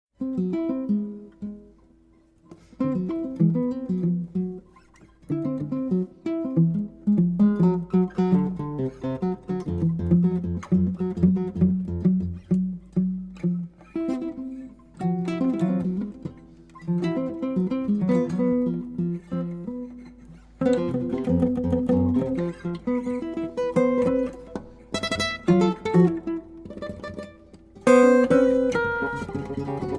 Two Master Guitarists + 18 tracks = Acoustic Improv Heaven